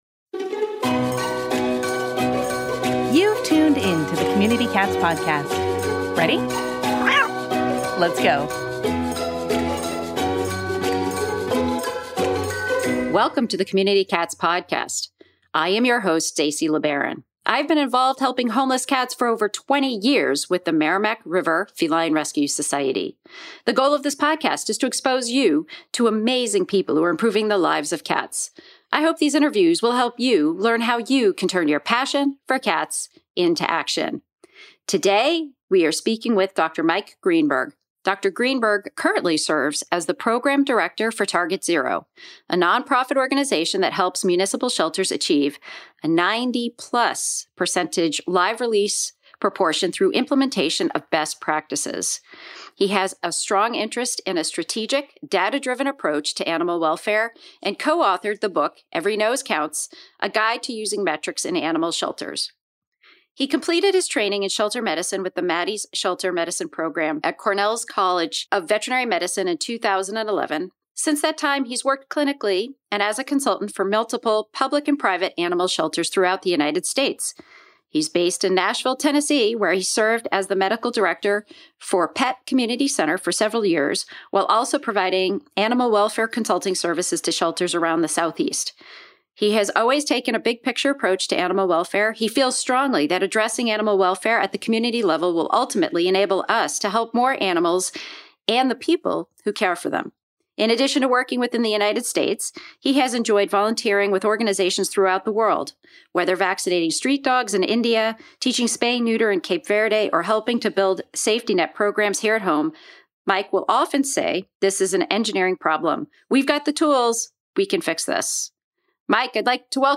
Interview!